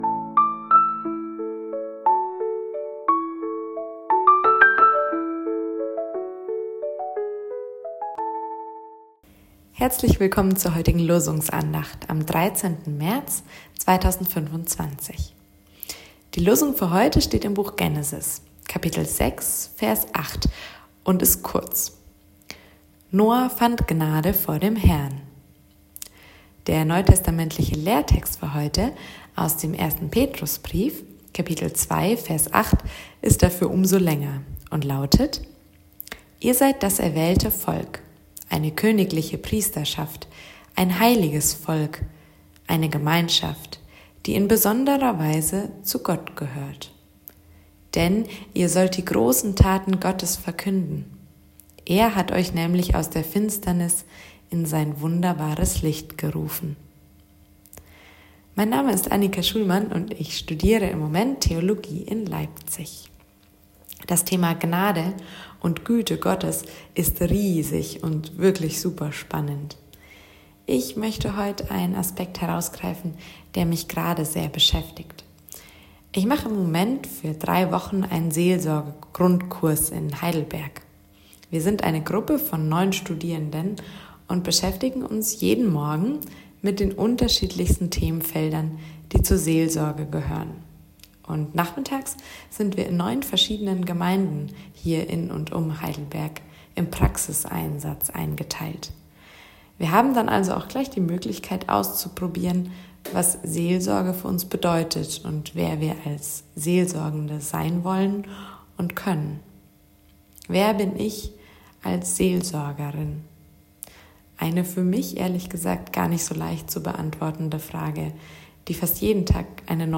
Losungsandacht für Donnerstag, 13.03.2025